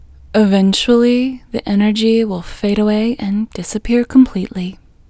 IN Technique First Way – Female English 20